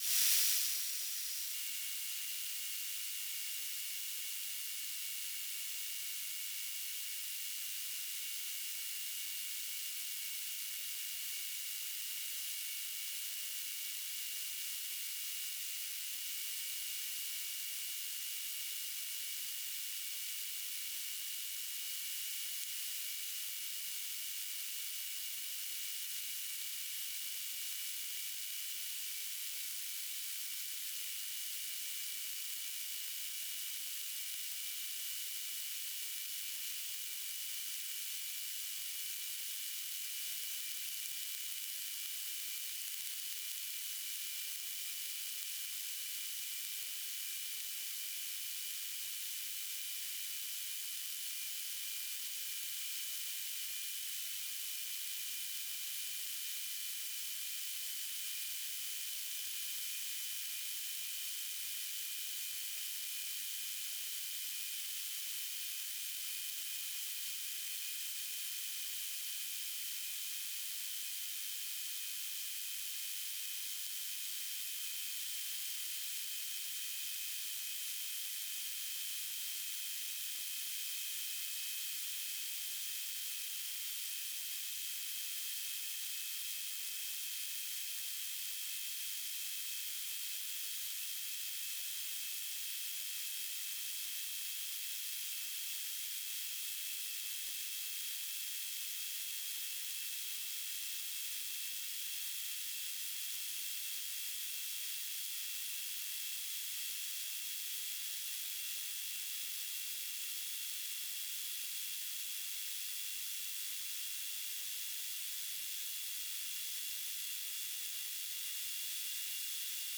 "transmitter_description": "BPSK Telemetry",